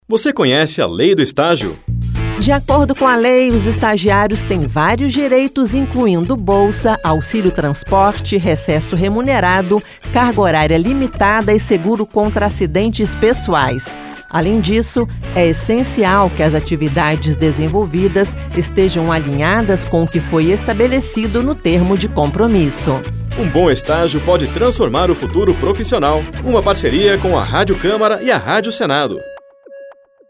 Esta campanha da Rádio Câmara e da Rádio Senado traz cinco spots de 30 segundos sobre a Lei do Estágio: seus direitos, obrigações e os principais pontos da lei.